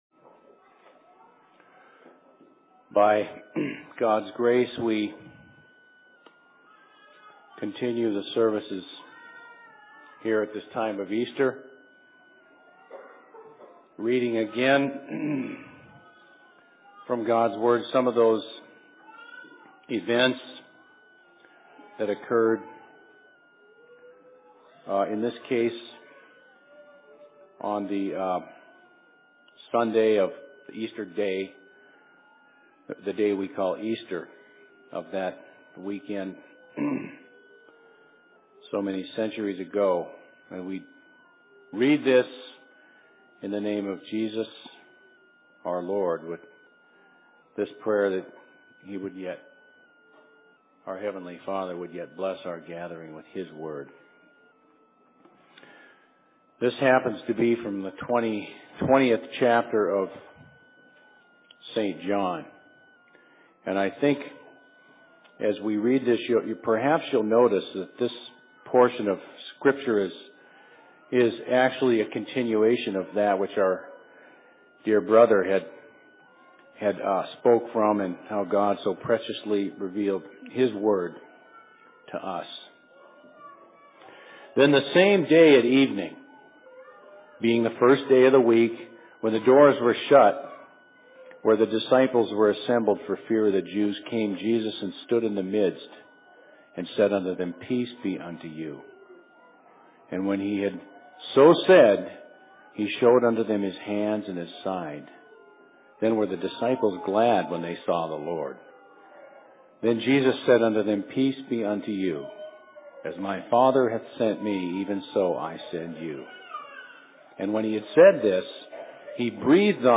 Sermon in Outlook 23.03.2008
Location: LLC Outlook